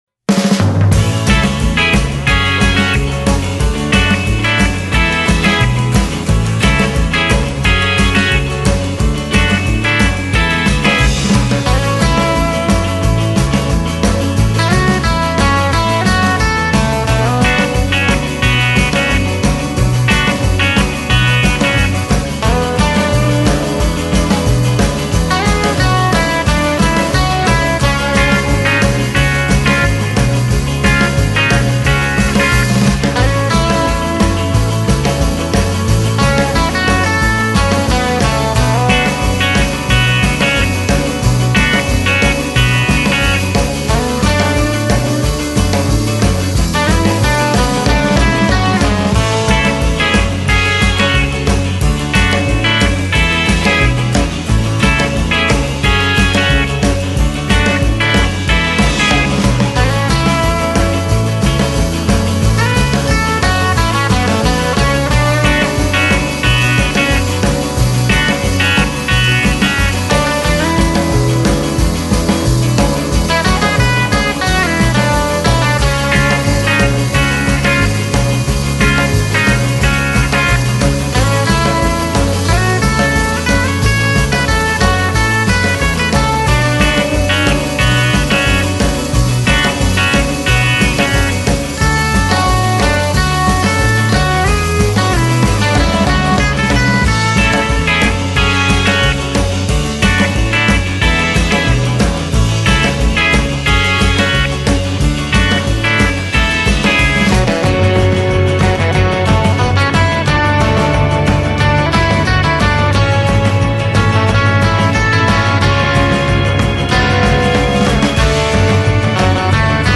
guitar-driven songs
studios in Los Angeles.